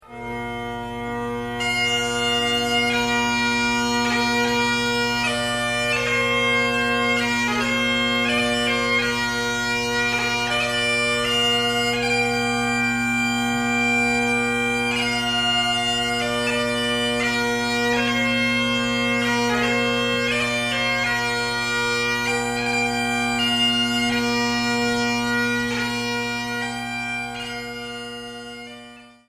Bagpipes (458KB)